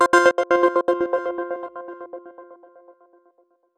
meteor.wav